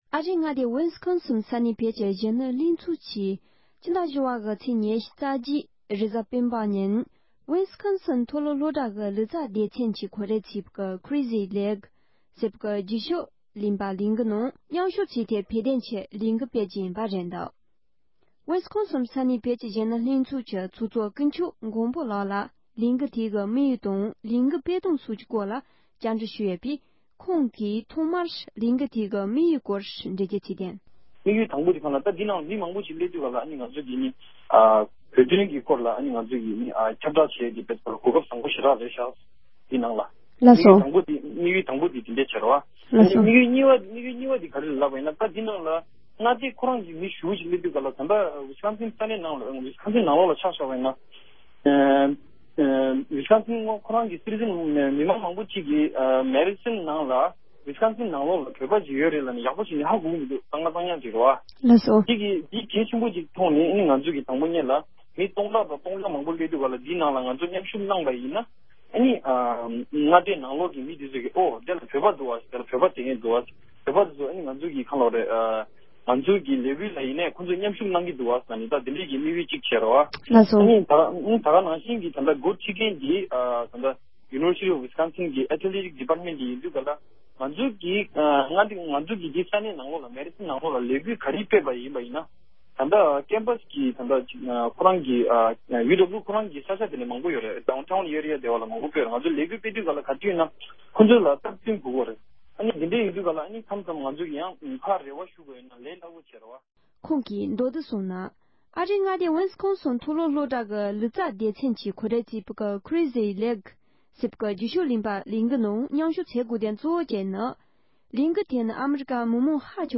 སྒྲ་ལྡན་གསར་འགྱུར། སྒྲ་ཕབ་ལེན།
འབྲེལ་ཡོད་མི་སྣར་བཅར་འདྲི་ཞུས་པ་ཞིག་ཉན་རོགས་ཞུ།